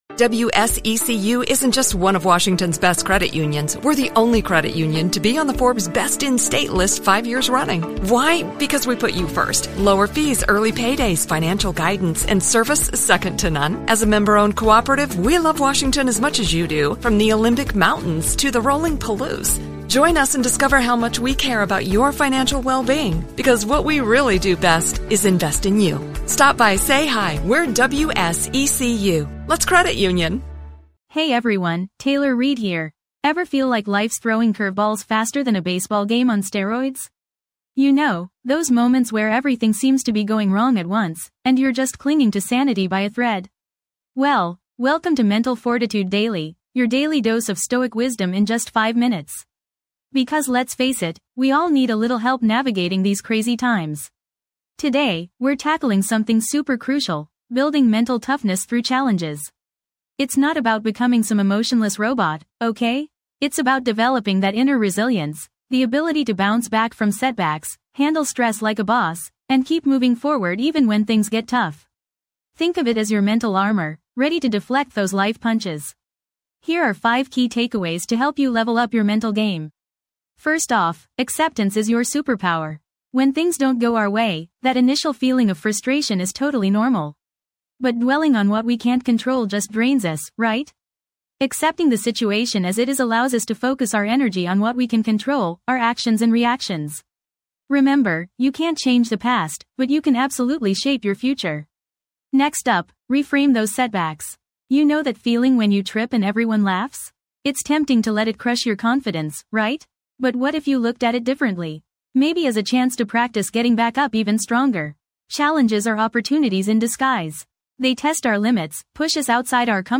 Podcast Category: Self-help, Personal Development, Motivation, Inspirational Talks
This podcast is created with the help of advanced AI to deliver thoughtful affirmations and positive messages just for you.